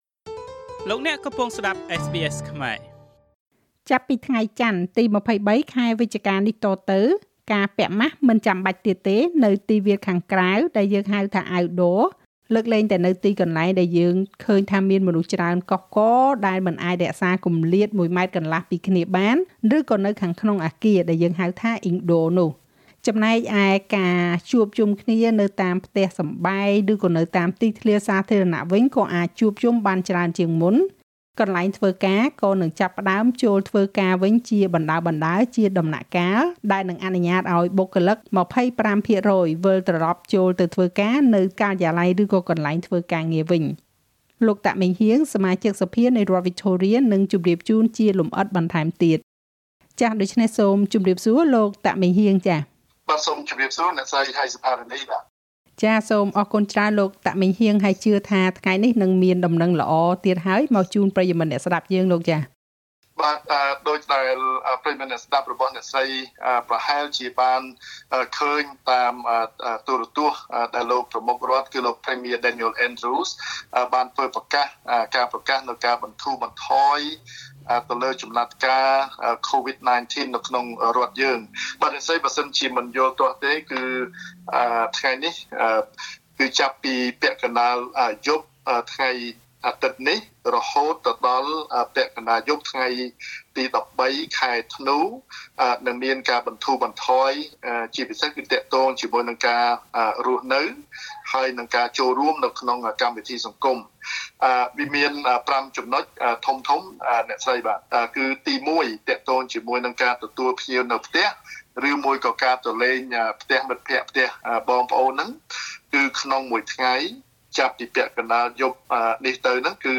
ចាប់ពីថ្ងៃចន្ទទី២៣ ខែវិច្ឆិកានេះទៅ នៅរដ្ឋវិចថូថៀ ការពាក់ម៉ាសមិនចាំបាច់ទៀតទេ នៅទីវាលខាងក្រៅ (outdoor) លើកលែងតែនៅកន្លែងដែលមានមនុស្សច្រើនកុះករដែលមិនអាចរក្សាគម្លាត១,៥ម៉ែត្រពីគ្នាបាន ឬនៅខាងក្នុងអគារ (indoor)។ កន្លែងធ្វើការក៏នឹងចាប់ផ្តើមចូលវិញជាបណ្តើរៗ រីឯការជួបជុំគ្នានៅតាមផ្ទះ ឬនៅតាមទីសាធារណៈក៏អាចធ្វើបានច្រើនជាងមុន។ លោក តាក ម៉េងហ៊ាង សមាជិកសភានៃរដ្ឋវិចថូរៀ រៀបរាប់បន្ថែម។